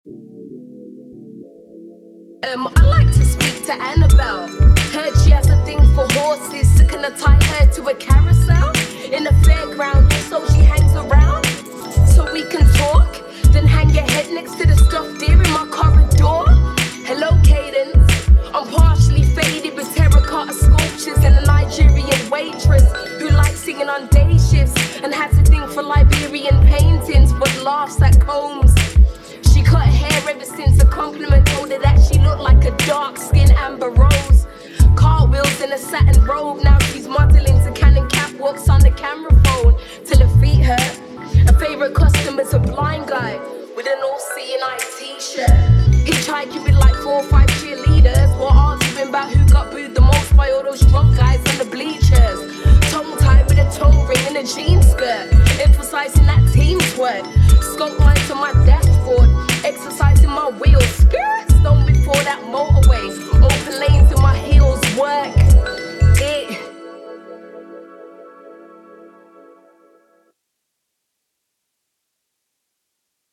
Cheers for doing that, I think it lends itself really nicely to the beat
Not sure who’s rapping